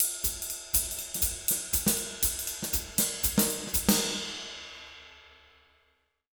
240SWING05-R.wav